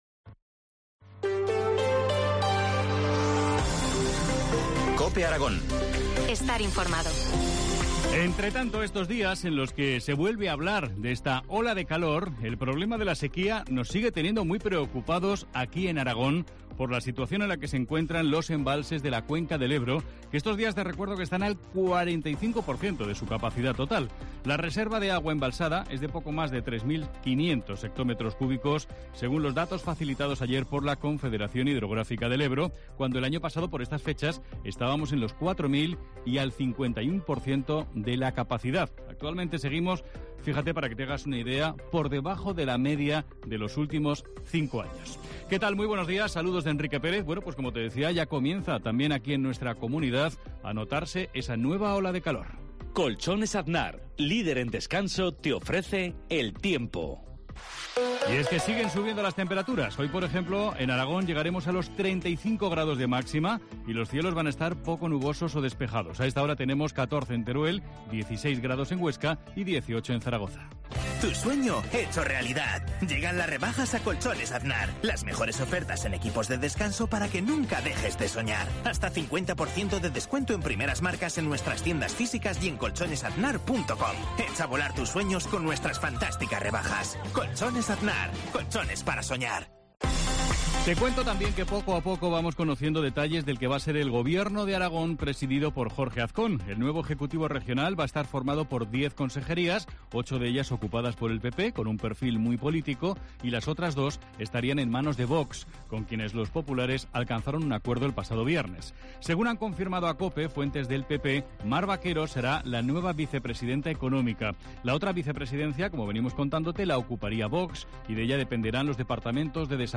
Informativo local